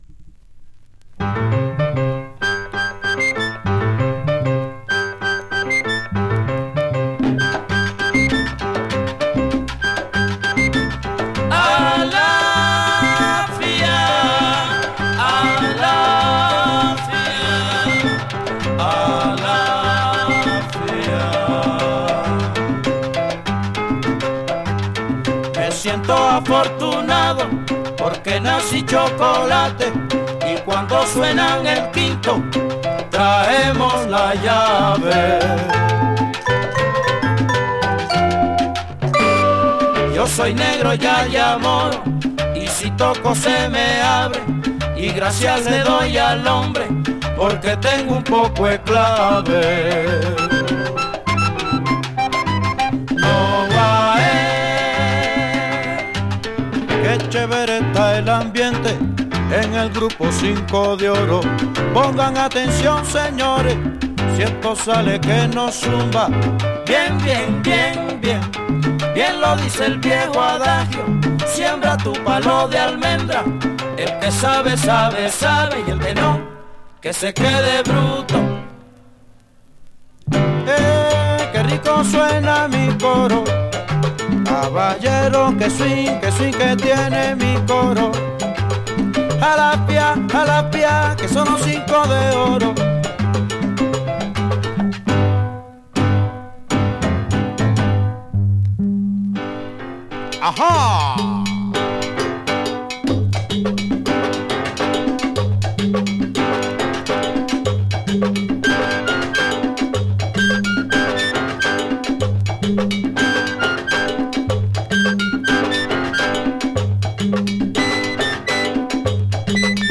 Boogaloo Colombiano